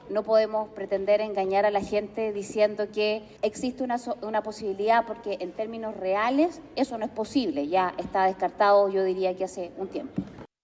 En Renovación Nacional, la diputada Camila Flores señaló que la derecha ya se dividió en ese objetivo y no logrará cambiar el panorama.